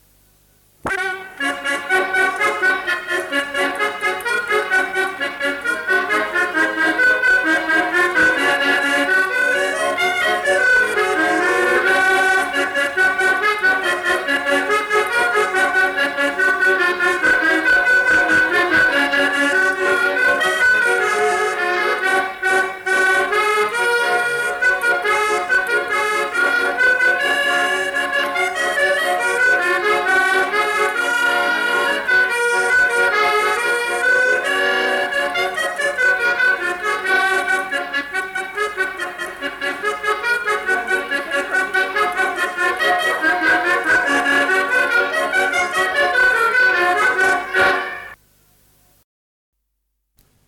Instrumental. Accordéon diatonique.
Aire culturelle : Cabardès
Lieu : Mas-Cabardès
Genre : morceau instrumental
Instrument de musique : accordéon diatonique
Il joue sans doute une figure de quadrille.